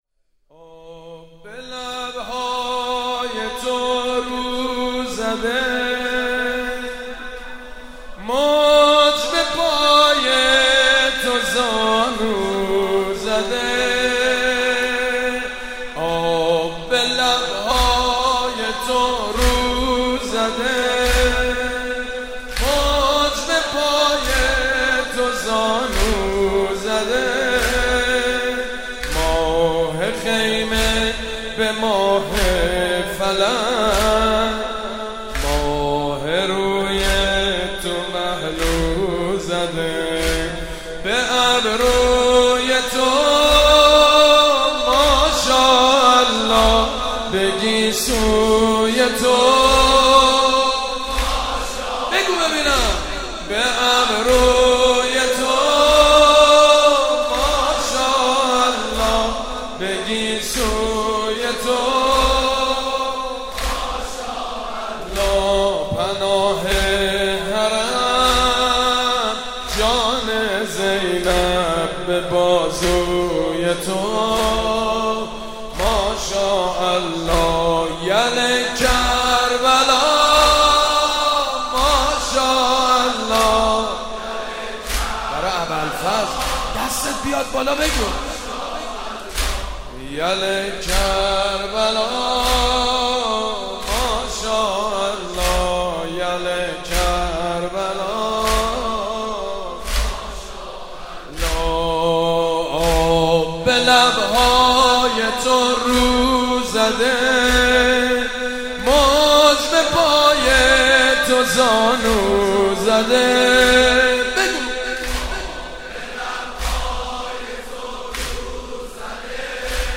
«ویژه مناسبت تخریب بقیع» زمینه: آب به لب های تو رو زده